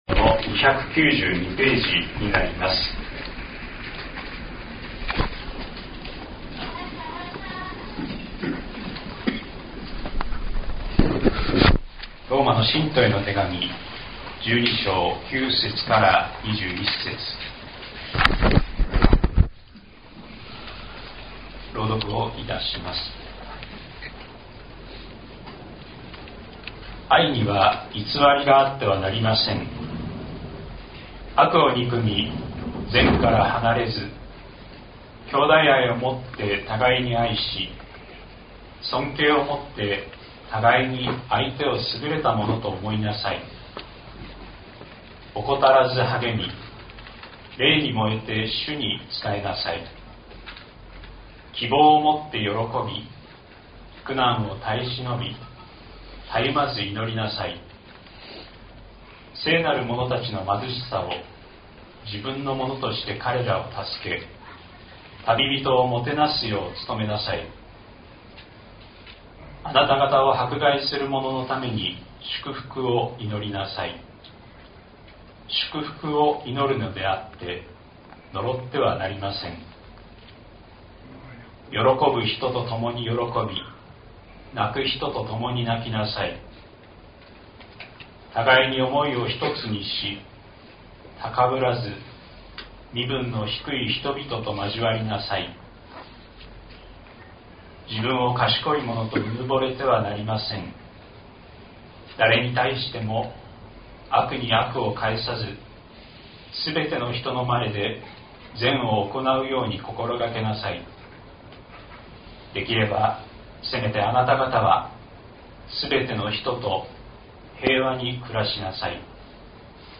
2026年02月01日朝の礼拝「共に喜び、共に泣く」西谷教会
音声ファイル 礼拝説教を録音した音声ファイルを公開しています。